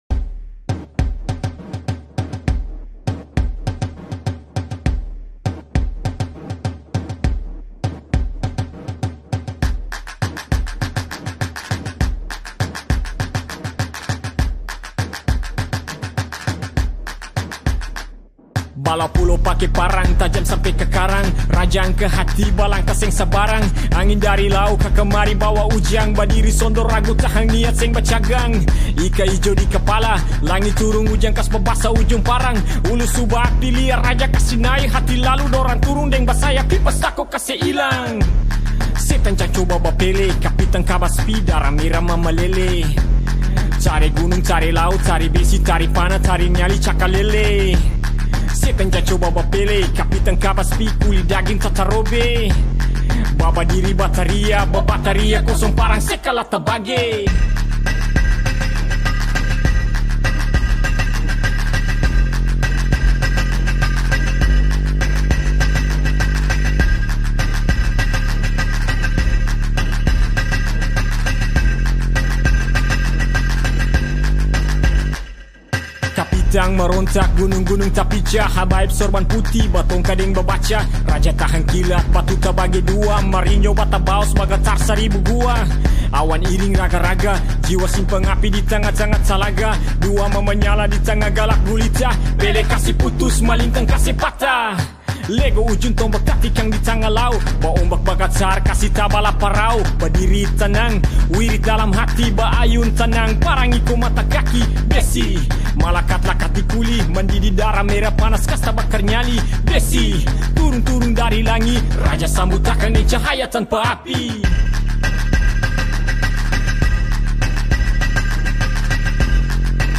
karya rap